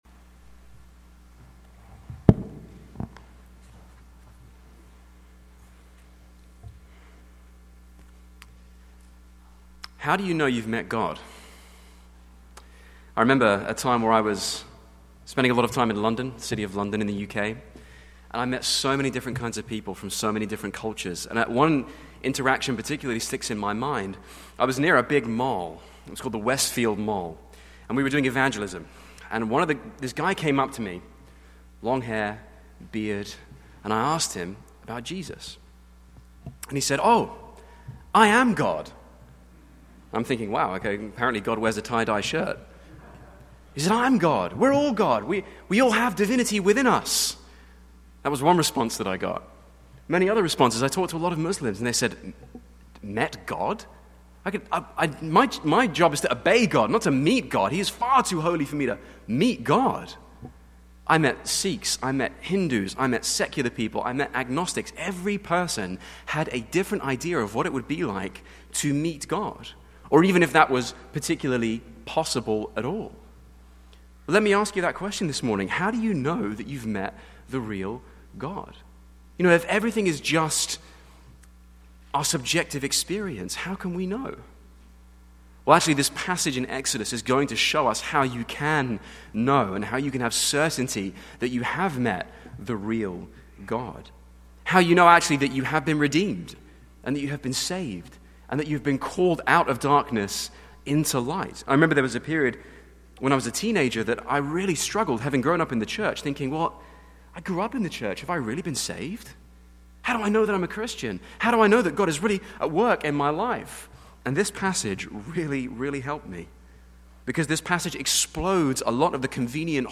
Passage: Exodus 2:23-3:15 Sermon